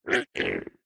Minecraft Version Minecraft Version 25w18a Latest Release | Latest Snapshot 25w18a / assets / minecraft / sounds / mob / strider / happy4.ogg Compare With Compare With Latest Release | Latest Snapshot